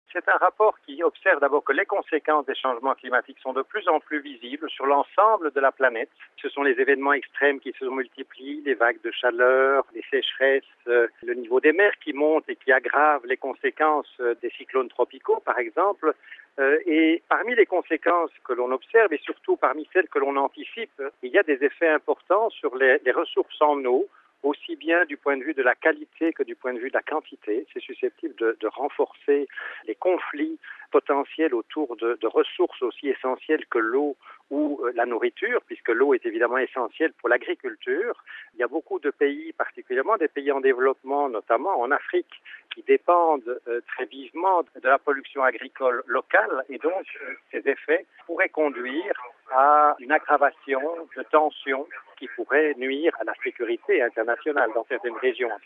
Autant de facteurs qui menacent la paix dans le monde pour le vice-président du GIEC Jean-Pascal van Ypersele, professeur de climatologie à l’université catholique de Louvain.